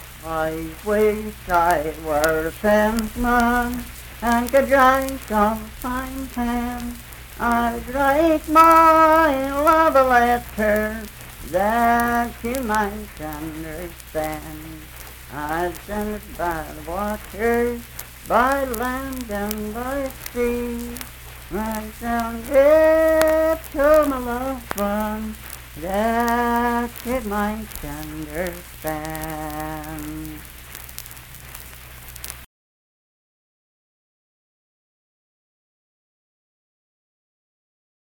Pretty Sara - West Virginia Folk Music | WVU Libraries
Unaccompanied vocal music performance
Voice (sung)